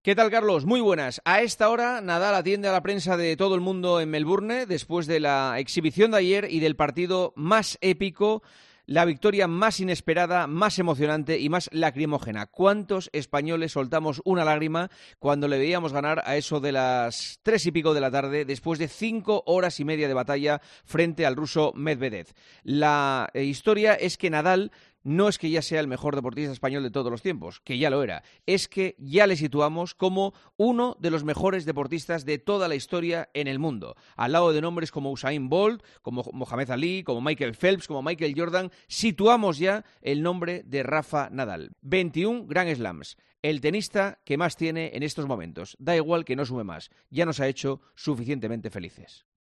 El comentario de Juanma Castaño